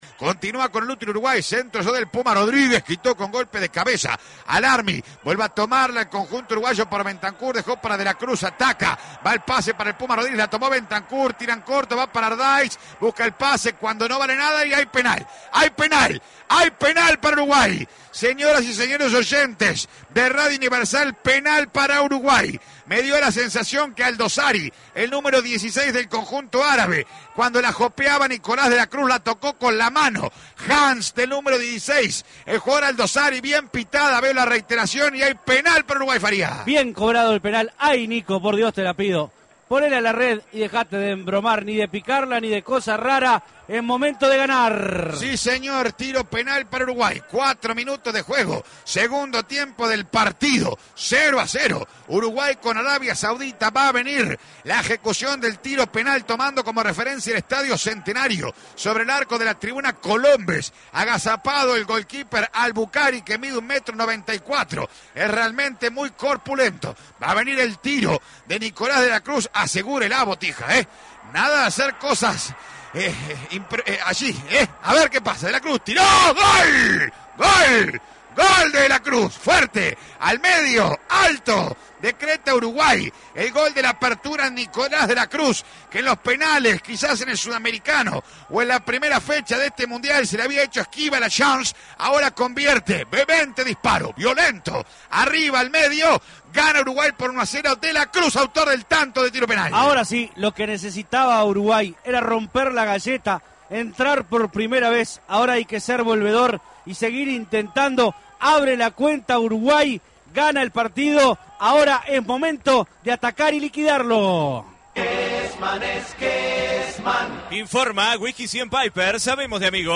Acá podés escuchar el relato del gol y el segundo tiempo de Uruguay 1 Arabia Saudita 0: